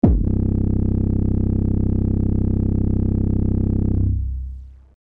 Sample 6 — Purge (808)
Sample-3-Purge-808.mp3